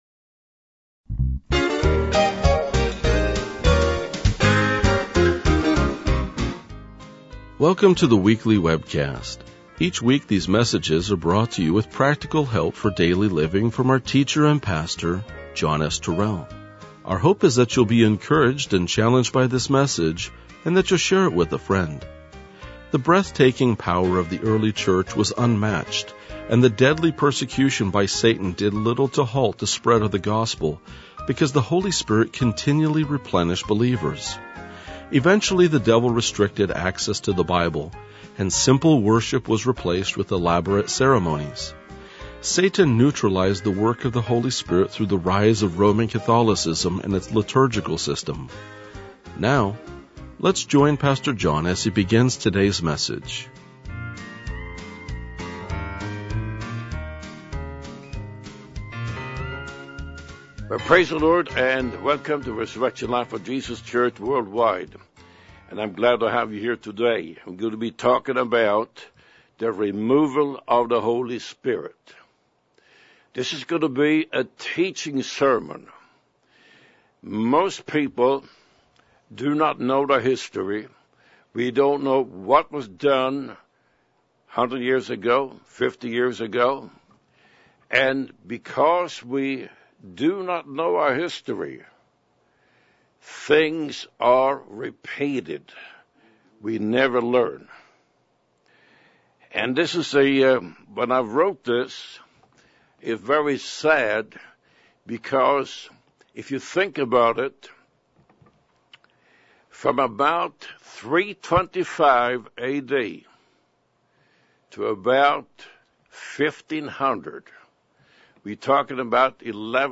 RLJ-2034-Sermon.mp3